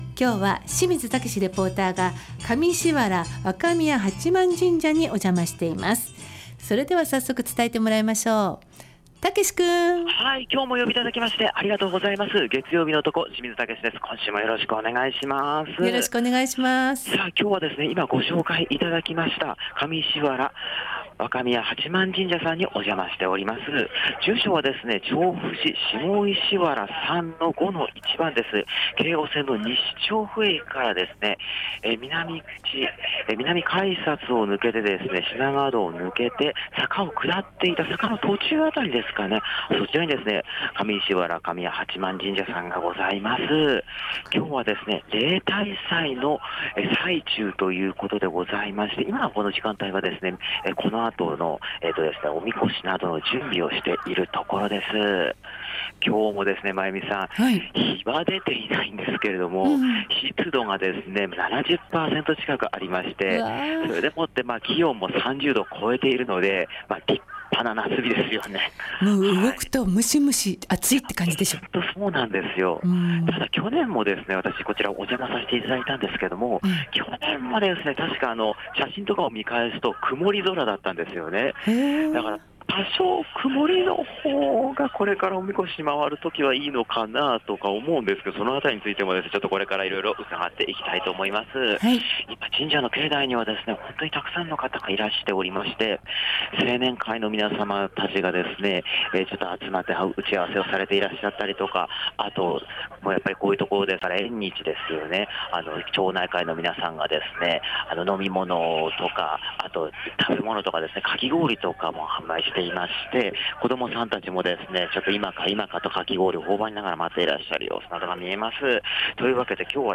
夏日の続く中で、比較的涼しめの曇り空の下からお届けした本日の街角レポートは、西調布にある上石原若宮八幡神社から例大祭のレポートでした！